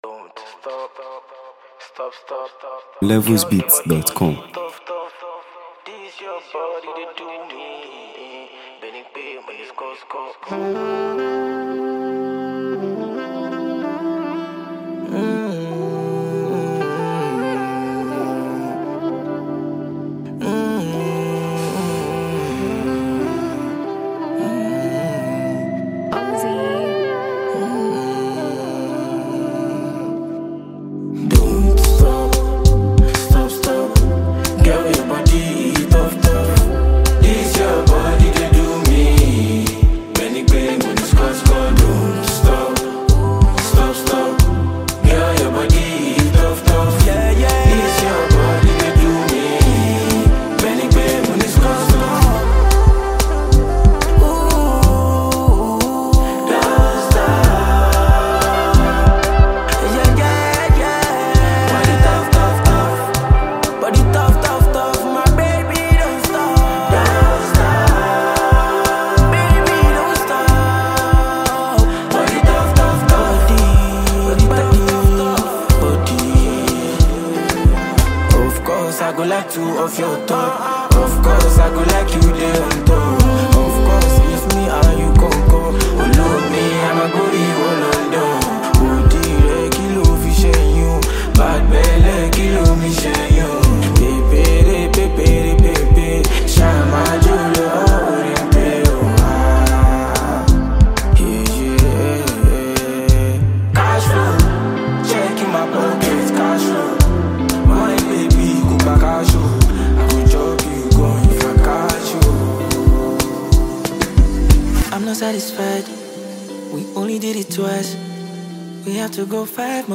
If you’re a fan of Afrobeat